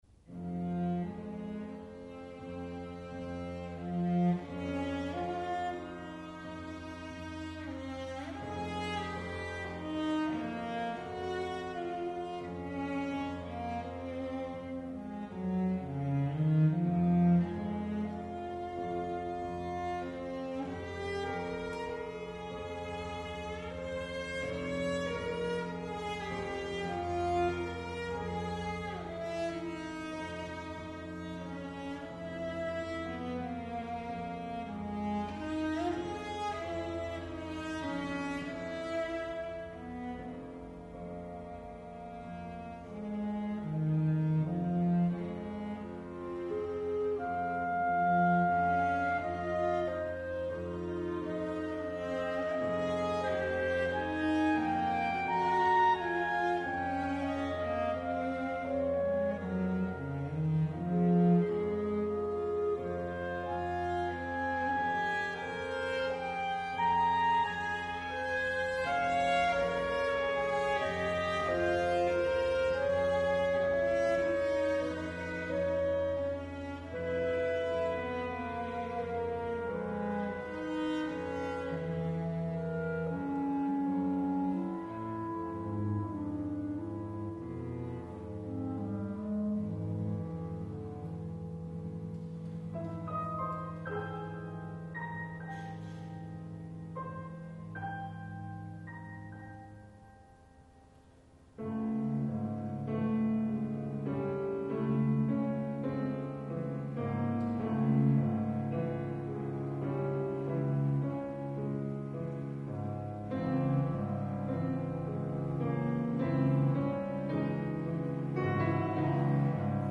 mournful second movement
for Clarinet, Cello and Piano